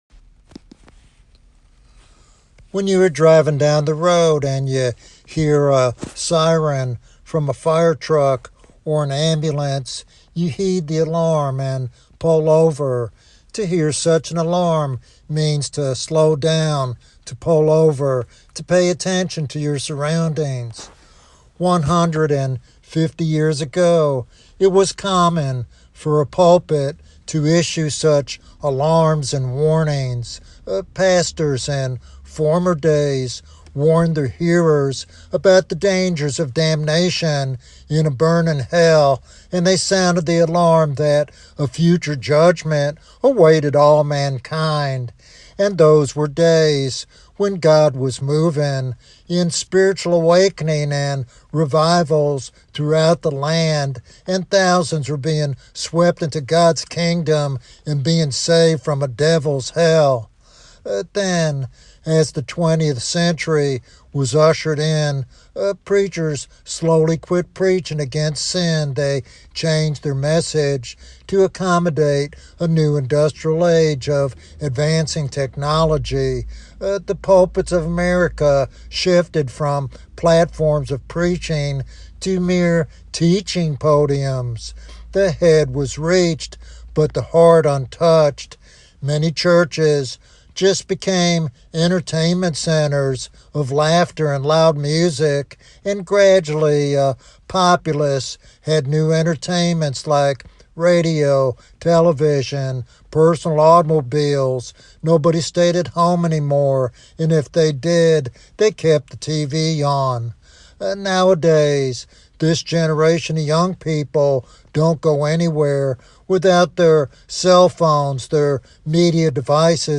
This sermon serves as a clarion call for revival and faithful gospel ministry in a generation increasingly indifferent to spiritual truth.